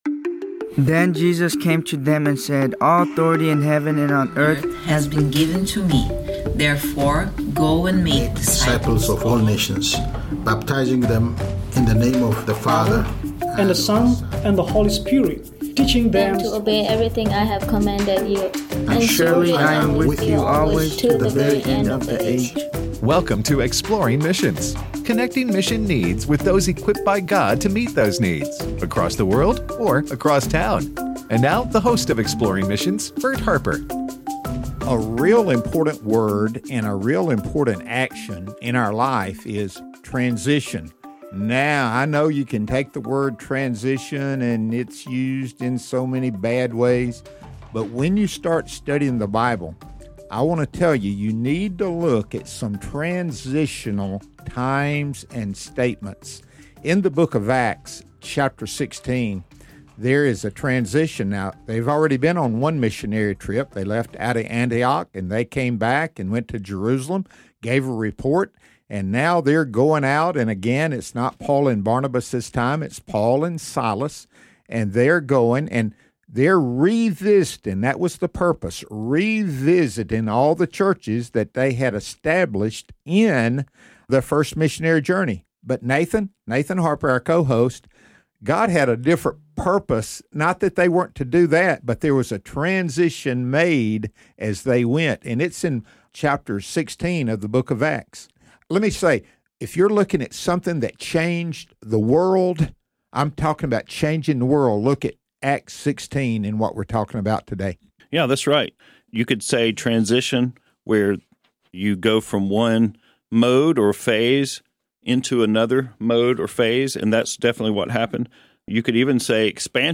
Indian Missions: A Conversation